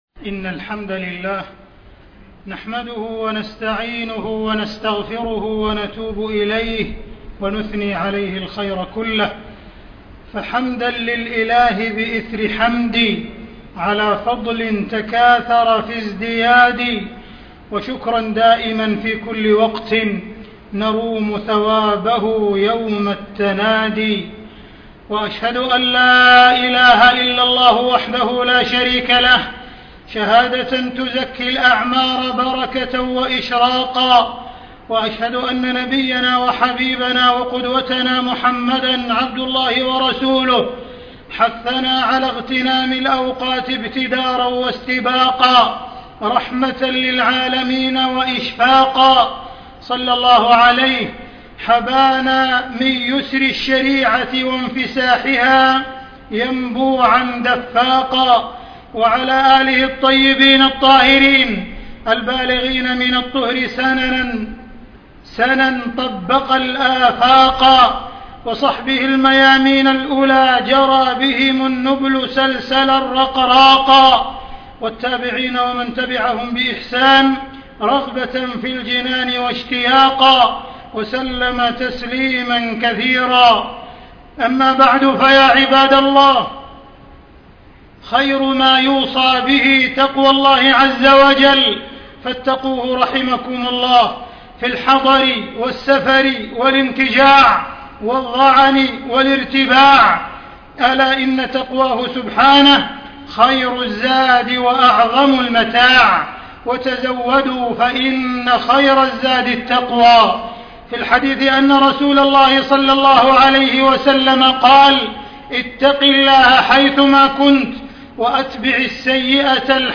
تاريخ النشر ١ شعبان ١٤٣٥ هـ المكان: المسجد الحرام الشيخ: معالي الشيخ أ.د. عبدالرحمن بن عبدالعزيز السديس معالي الشيخ أ.د. عبدالرحمن بن عبدالعزيز السديس فصل الصيف والإجازة